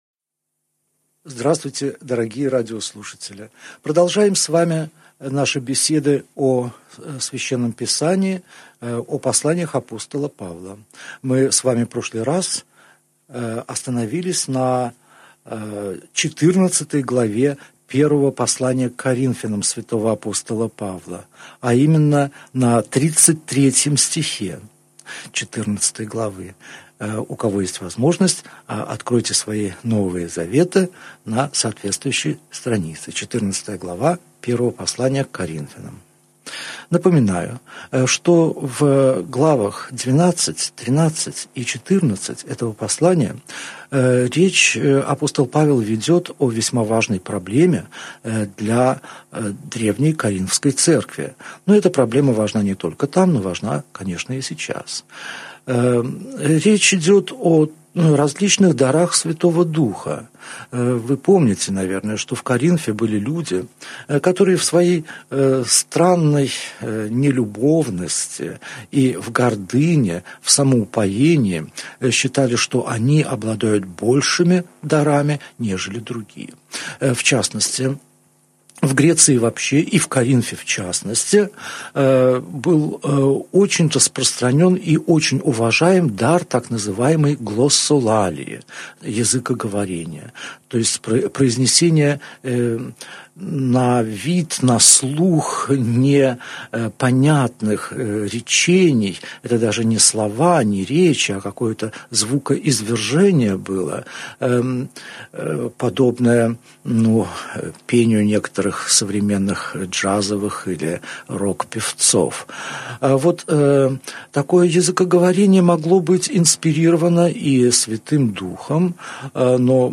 Аудиокнига Беседа 35. Первое послание к Коринфянам. Глава 14, стихи 15 – 40 | Библиотека аудиокниг